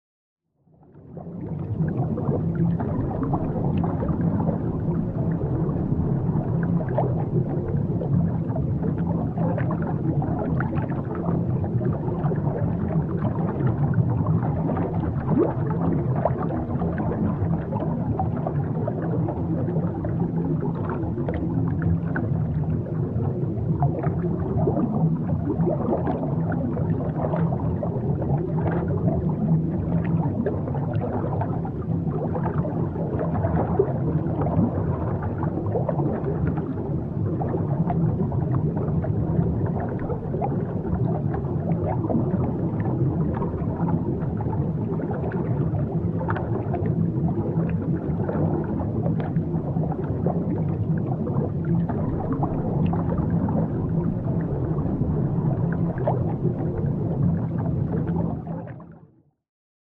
Звуки подводного мира
Звук, возникающий при погружении на небольшую глубину